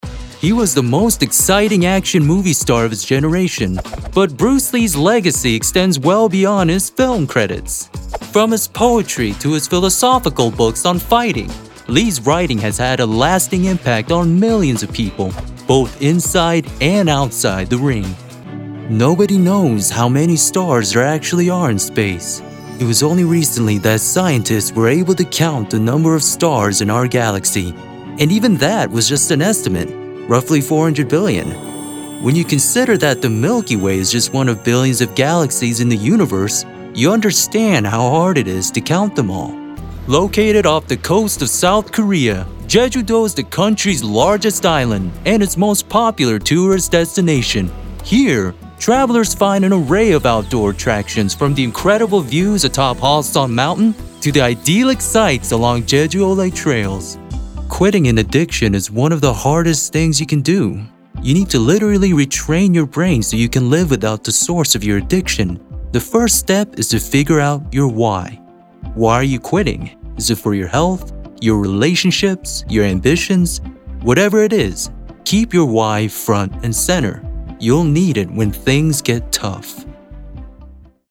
Voice Over Talent
Narration Demo
Laidback with a richness that adds a little something extra.
Warm, engaging, and approachable with a professional turnaround and top-quality audio.
Smooth and consistent, perfect for narration and other long form content.